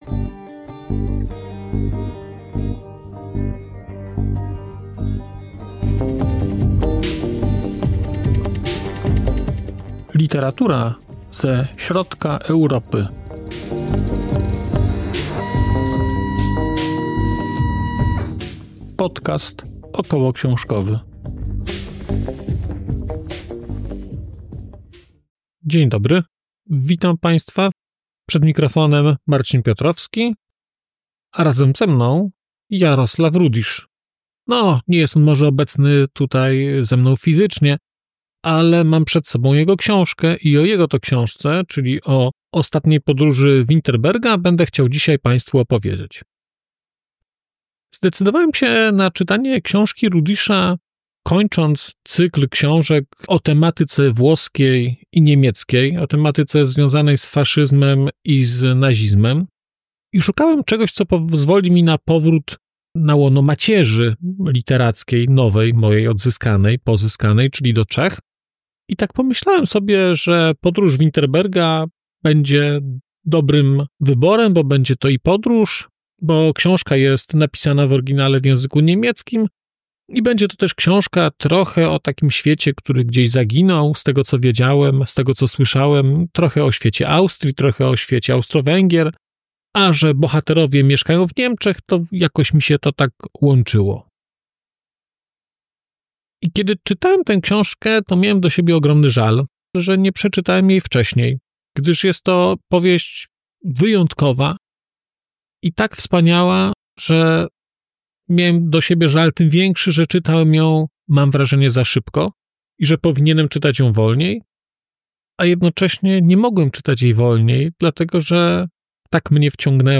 🔧 odcinek zremasterowany: 6.04.2025